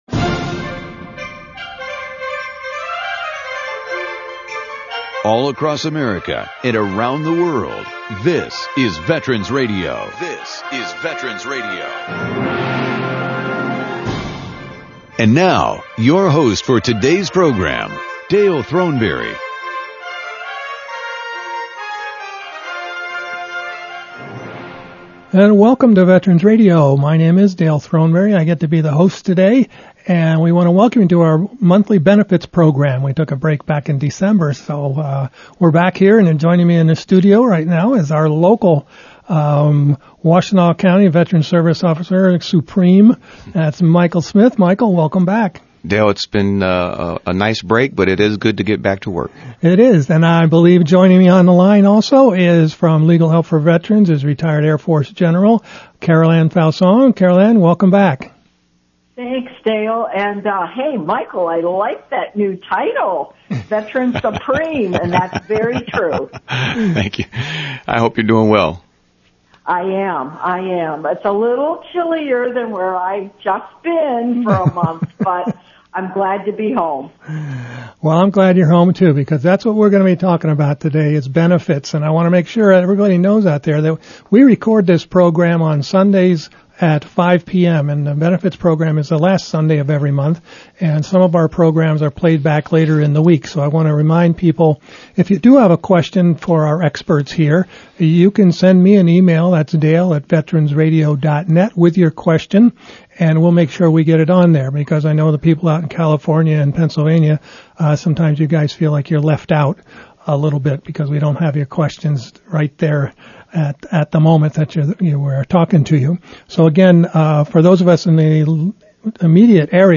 Listen in as our expert panel of VA service officers and providers discuss today’s healthcare and benefits earned by millions of veterans who have served.
Call us with your questions during the live broadcast!